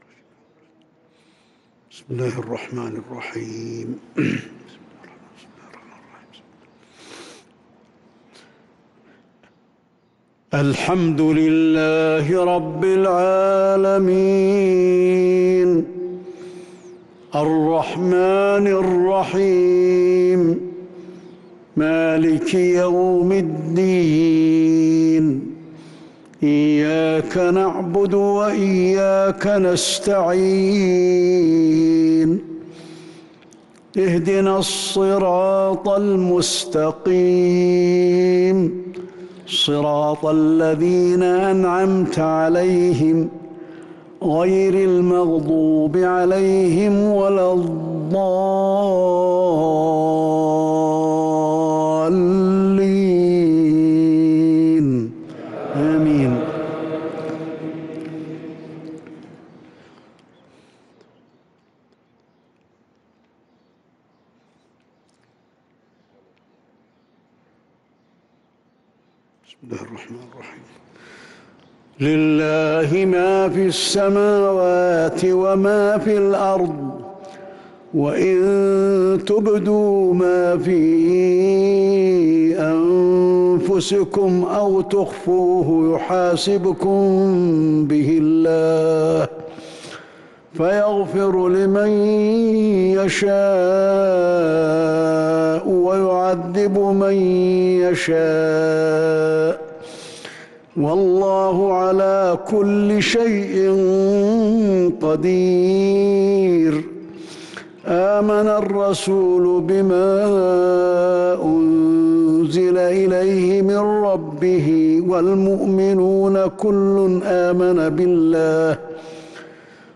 صلاة المغرب للقارئ علي الحذيفي 29 شعبان 1443 هـ
تِلَاوَات الْحَرَمَيْن .